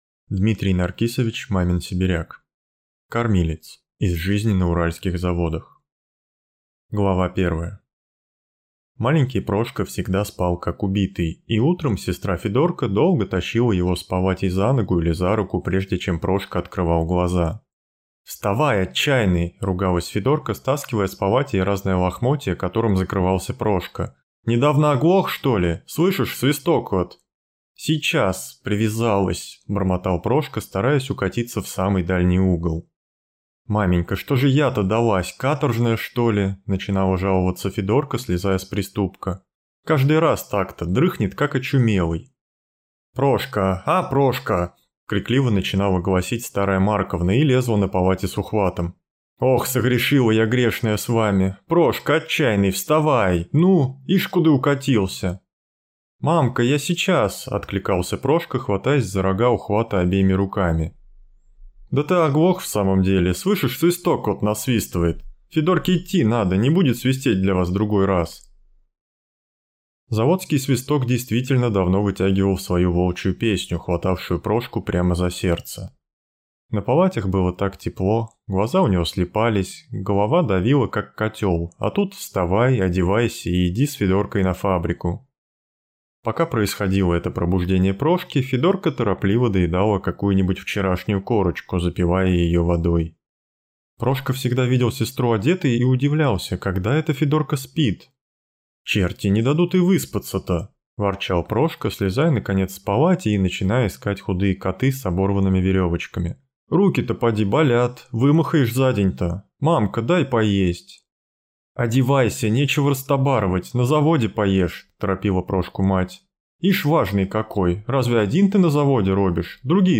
Аудиокнига Кормилец | Библиотека аудиокниг
Прослушать и бесплатно скачать фрагмент аудиокниги